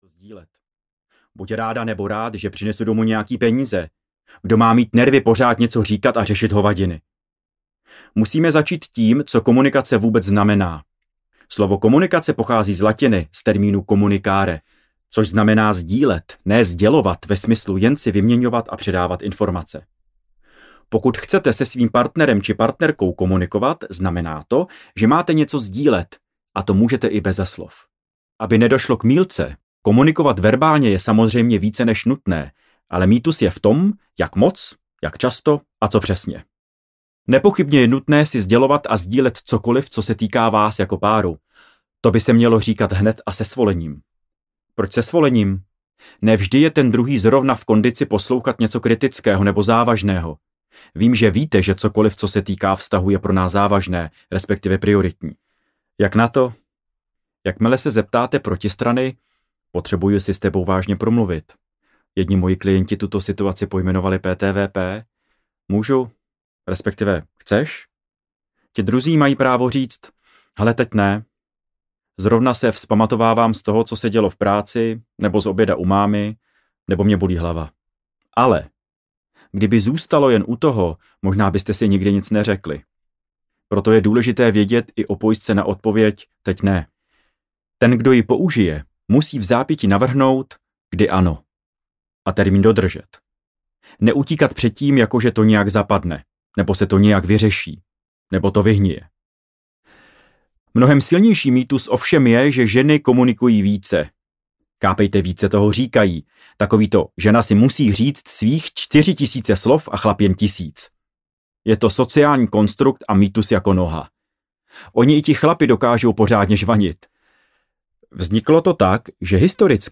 Vztahy a mýty audiokniha
Ukázka z knihy